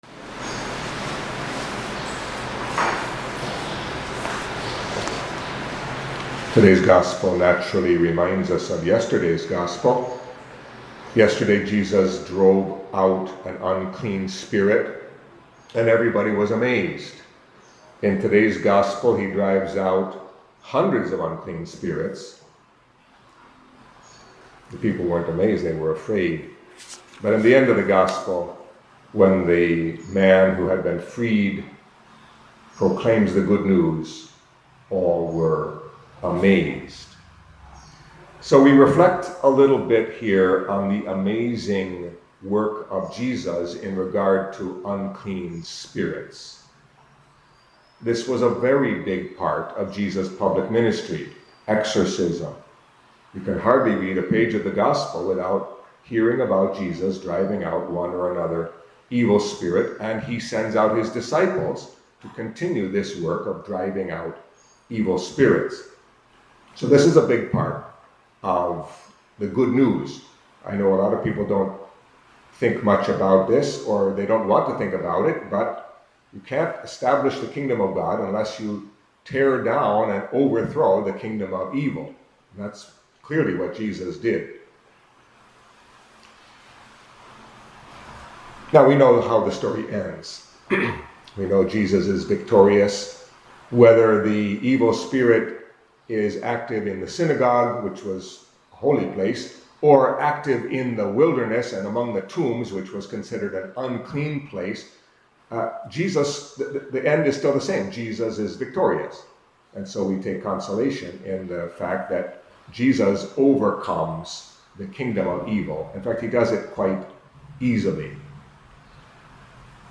Catholic Mass homily for Monday of the Fourth Week in Ordinary Time